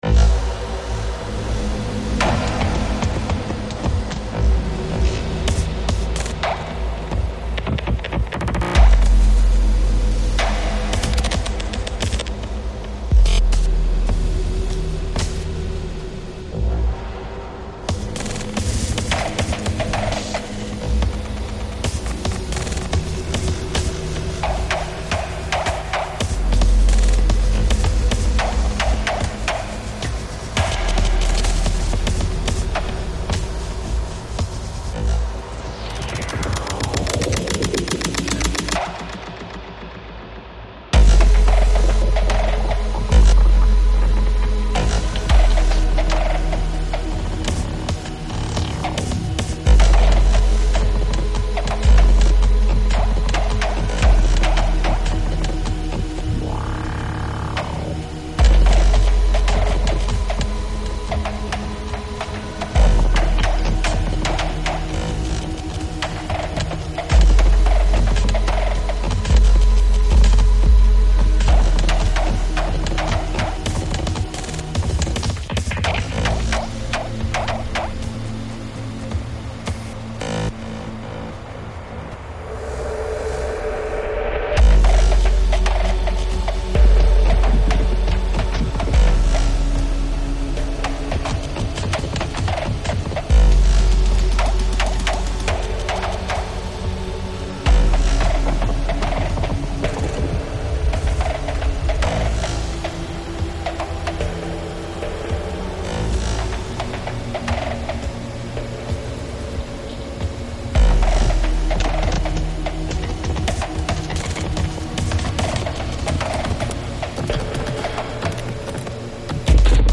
I was keen to both explore the rhythms and that sense of being joined by spirits - the ambiguity about who plays; as much in the lack of information about the actual individuals as in a spiritual way.
Eventually these original and new patterns, skipping and jolting through an imaginary forest - meeting, somehow, across time - coalesced into this piece of music. Gooma (tree drum) played with sticks